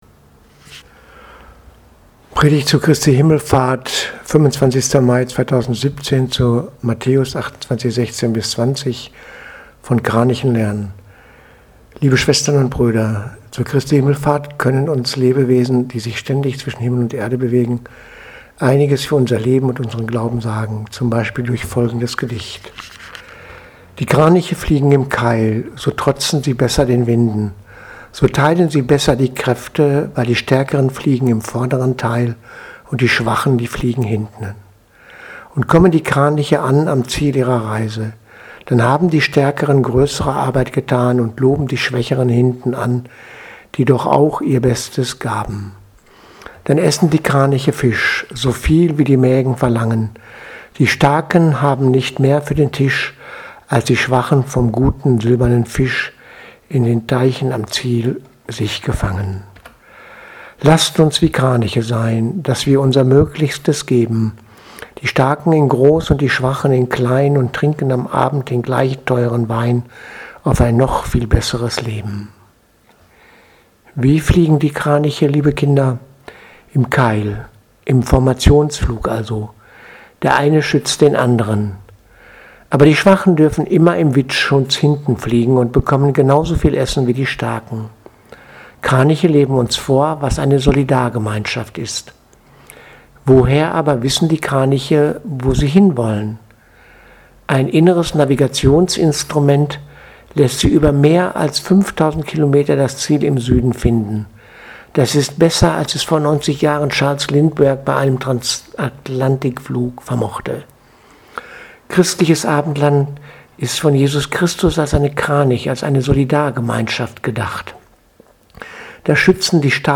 Predigt vom 25.5.2017 Christi Himmelfahrt